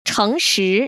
[chéng‧shí] 청스  ▶